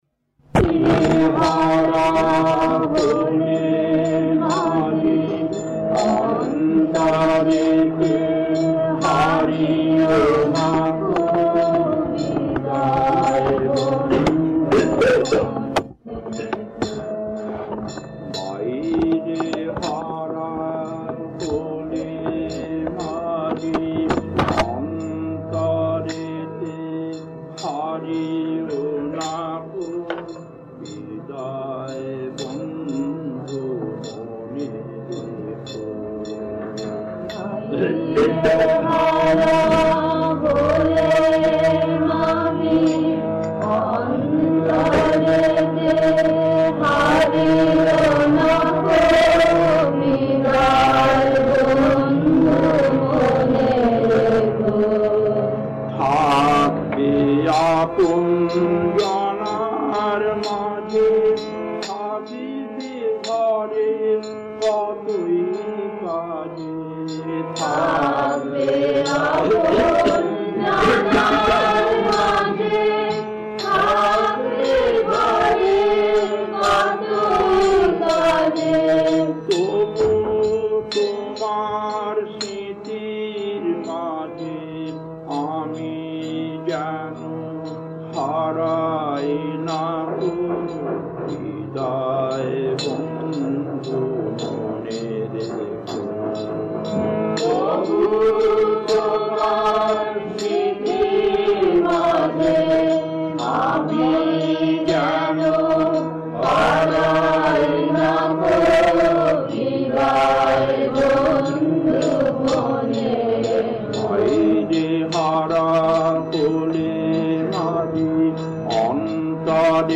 Kirtan B4-1 Chennai Railway Station, 68 minutes 1.